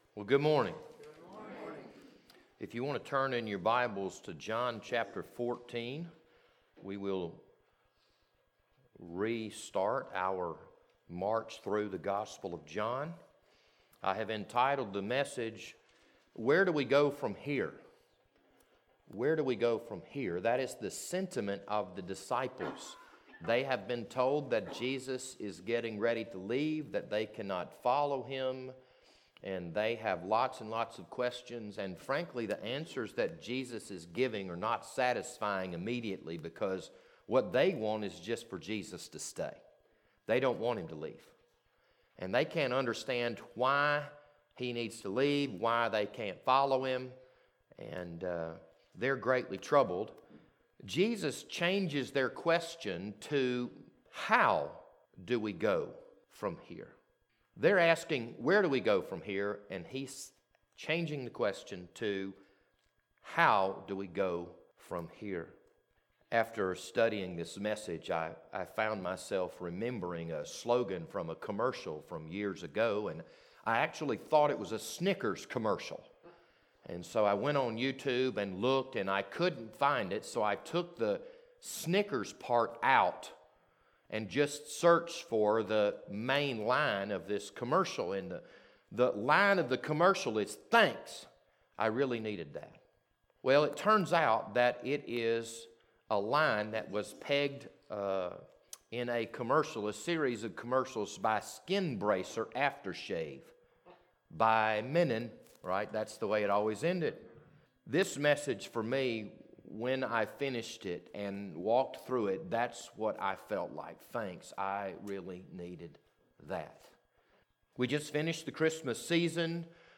This Sunday morning sermon was recorded on December 27th, 2020.